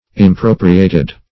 Impropriated - definition of Impropriated - synonyms, pronunciation, spelling from Free Dictionary
Impropriate \Im*pro"pri*ate\, v. t. [imp. & p. p.